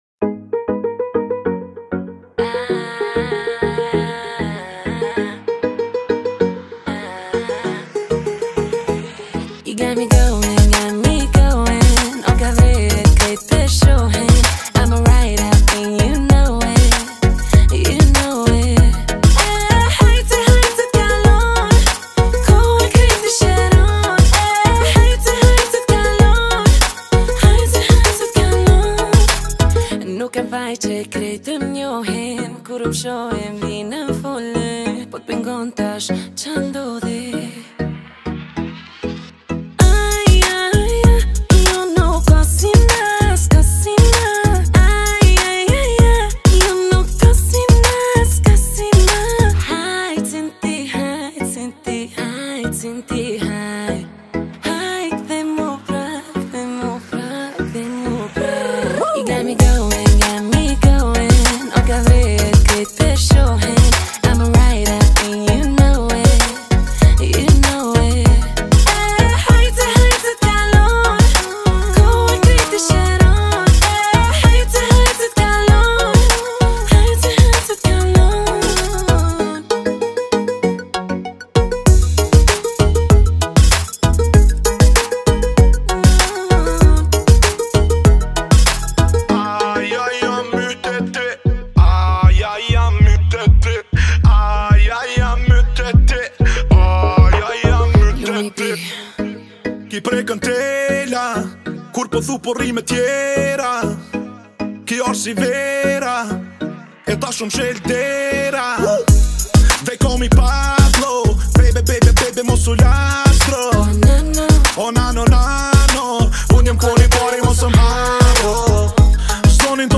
это захватывающий трек в жанре поп с элементами R&B